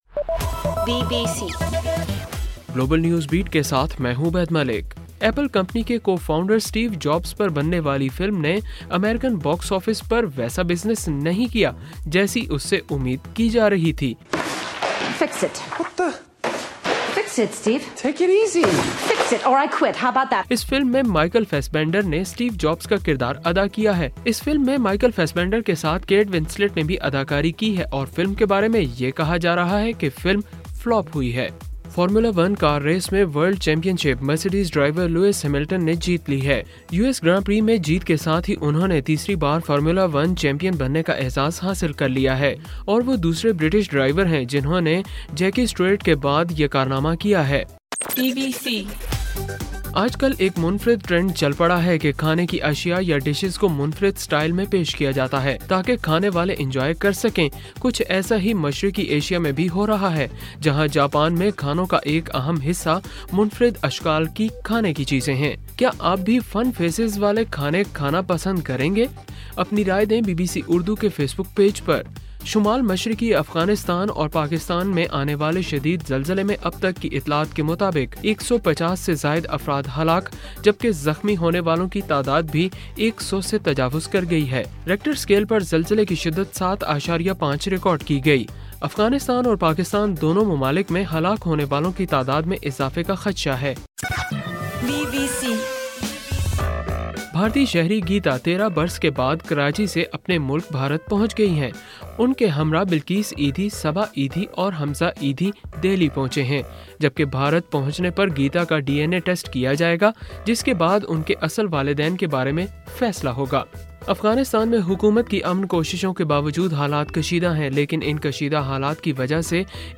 اکتوبر 26: رات 11 بجے کا گلوبل نیوز بیٹ بُلیٹن